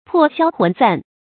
魄消魂散 注音： ㄆㄛˋ ㄒㄧㄠ ㄏㄨㄣˊ ㄙㄢˋ 讀音讀法： 意思解釋： 同「魂飛魄散」。